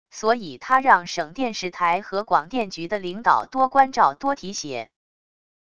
所以他让省电视台和广电局的领导多关照多提携wav音频生成系统WAV Audio Player